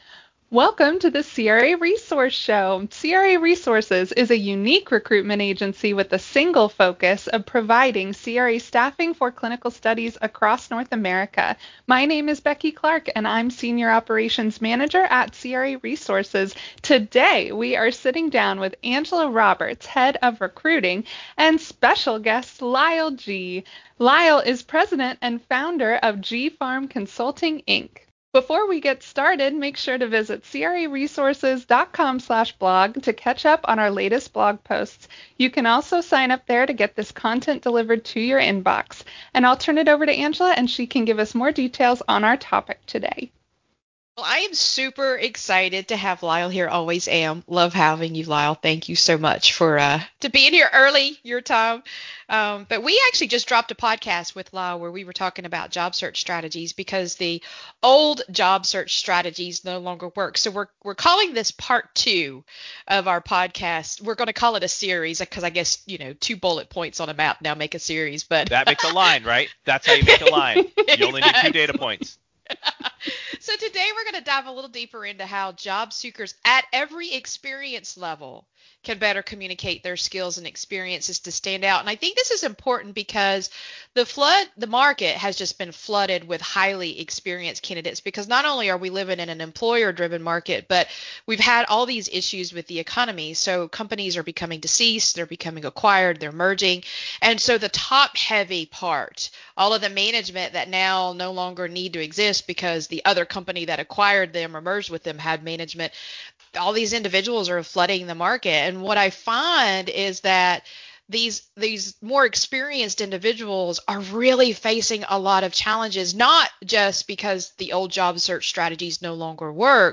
Whether you are a seasoned pro or still figuring out which way is up, this conversation digs deep into what it really means to be flexible without settling for just any paycheck.